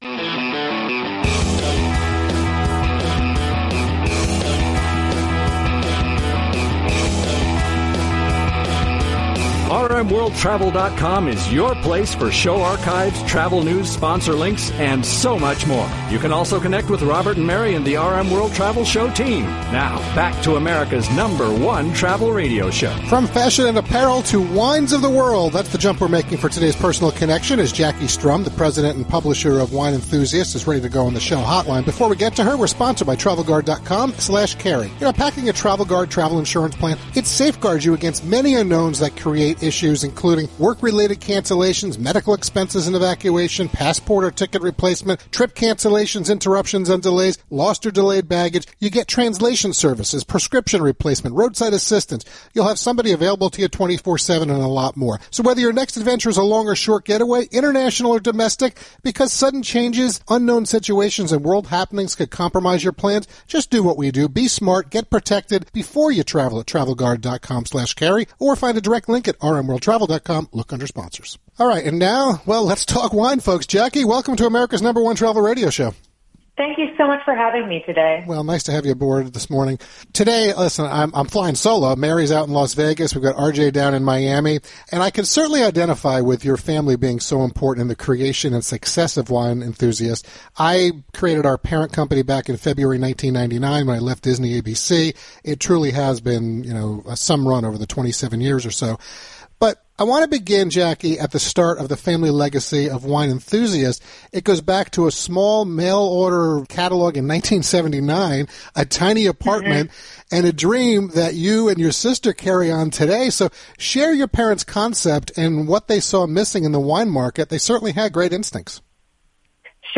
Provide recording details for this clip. live national broadcast of America’s #1 Travel Radio Show